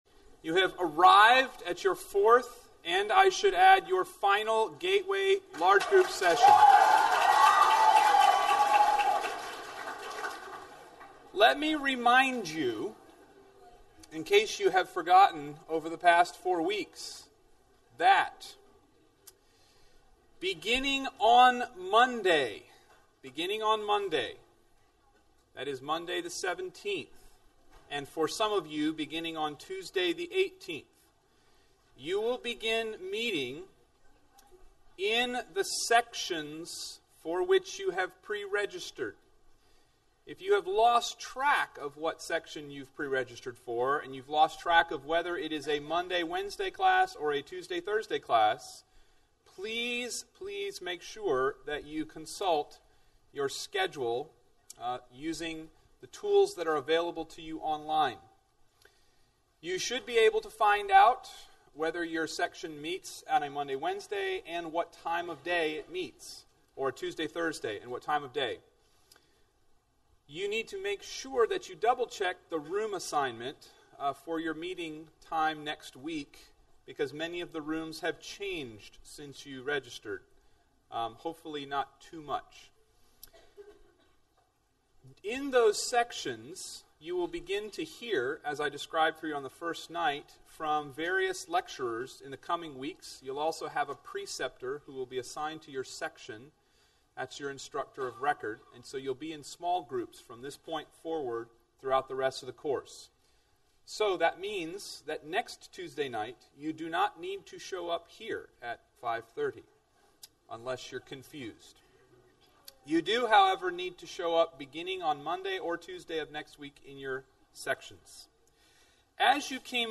Address: Politics and All That